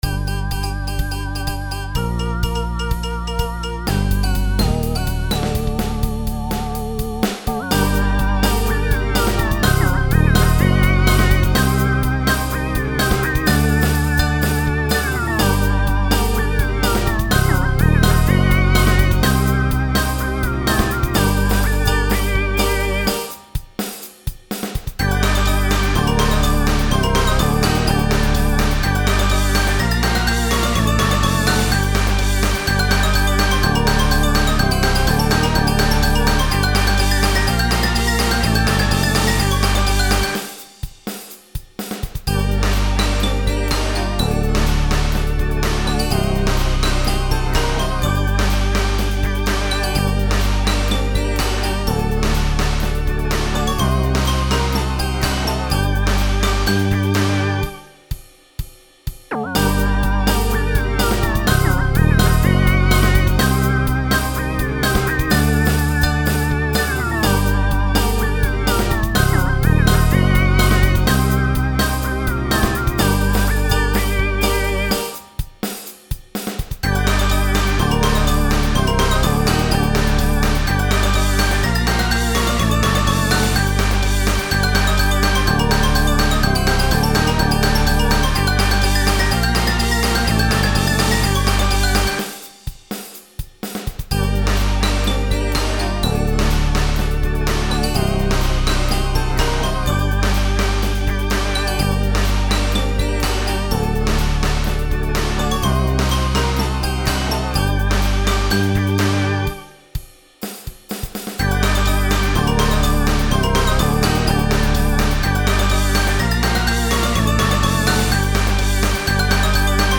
I really really adore how it came out, it feels like a halloween rollercoaster <3